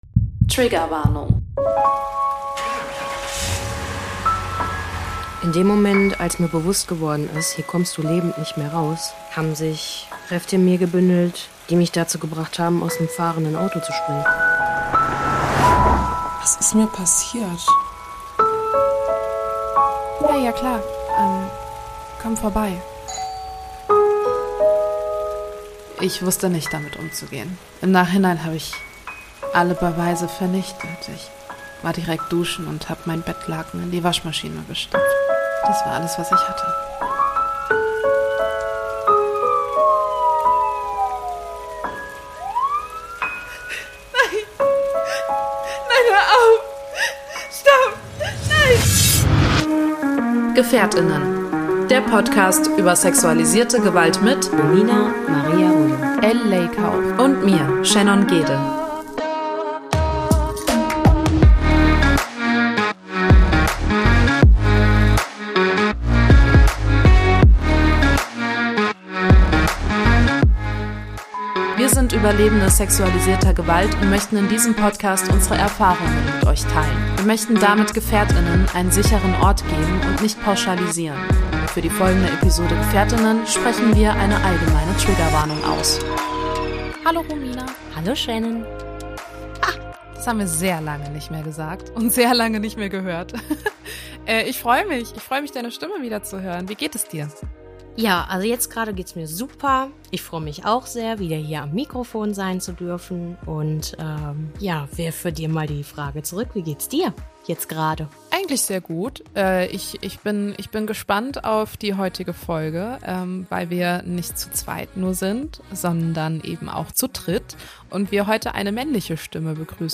ist bei uns zu Gast um über die aktuelle Lage vom Heimwegtelefon zu berichten!